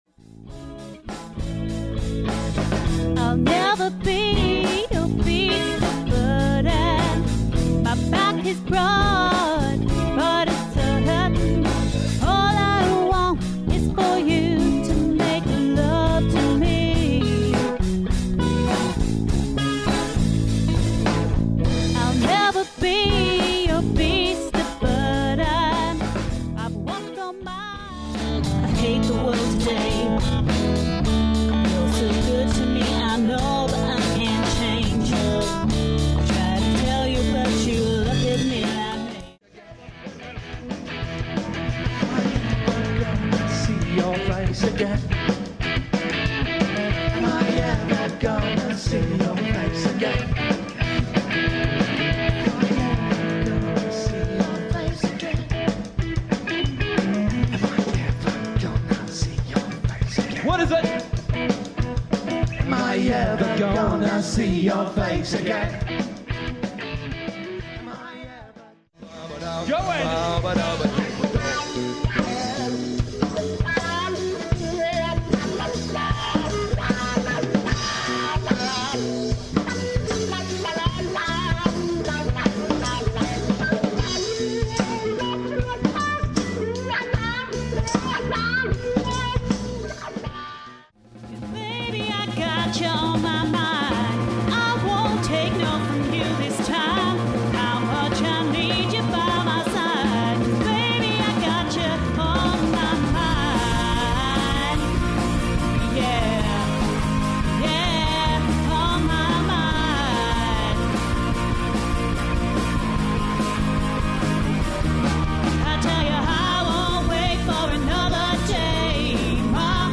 Lead Vocals.
Guitar
Bass
Drums